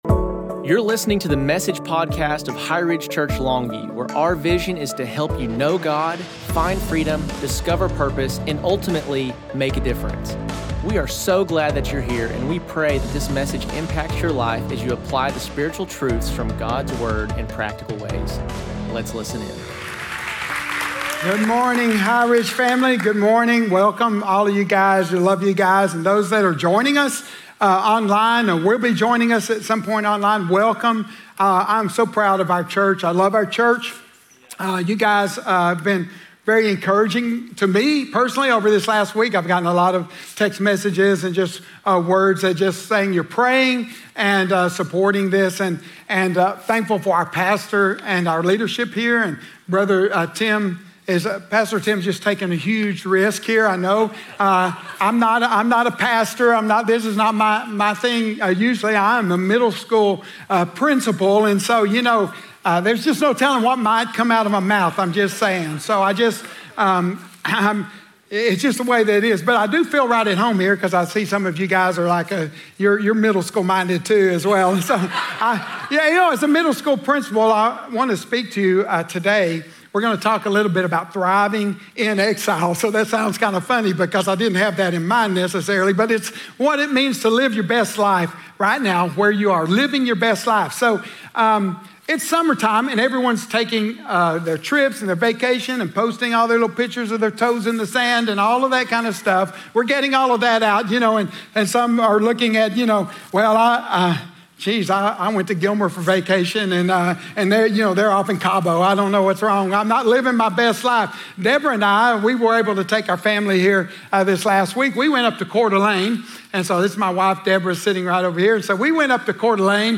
Message: Thriving in Exile: Living Your Best Life Right Where You Are